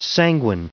1020_sanguine.ogg